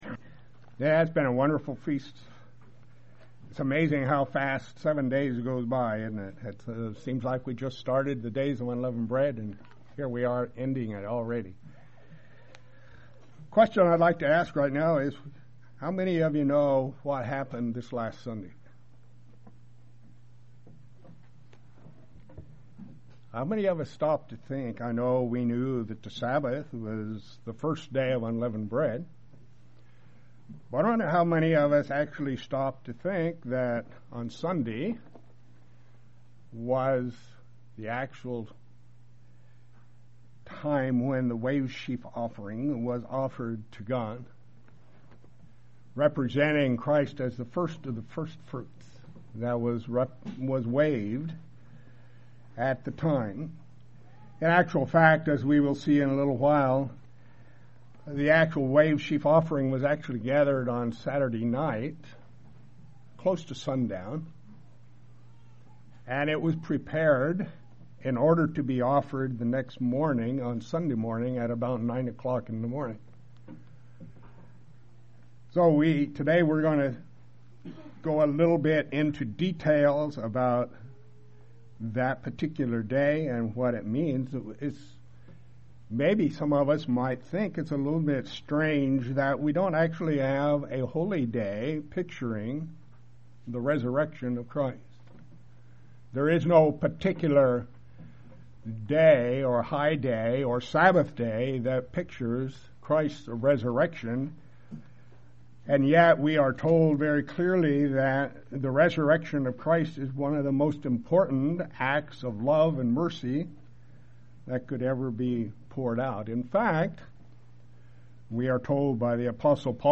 What does this little considered offering really mean? This sermon will show that the wave sheaf (omer) represents the actual resurrection of our Lord and Master.